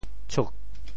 潮州拼音“cug4”的详细信息
国际音标 [ts]